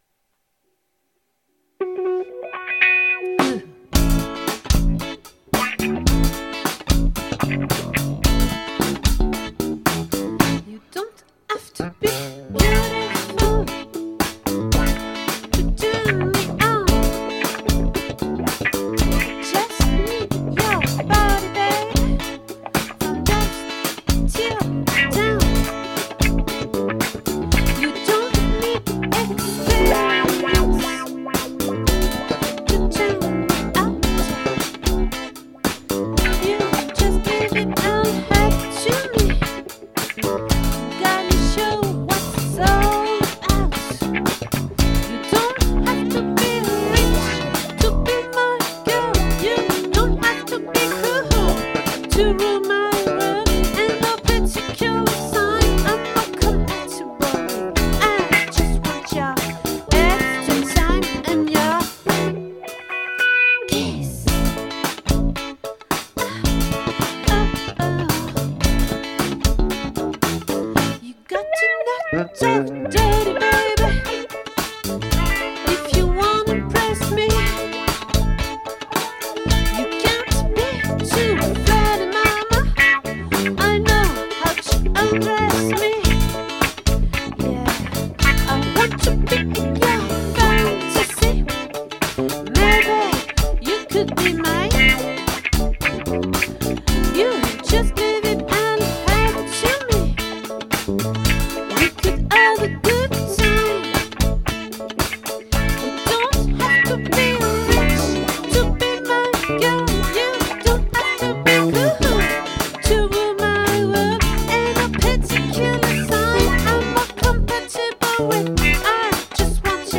🏠 Accueil Repetitions Records_2022_01_05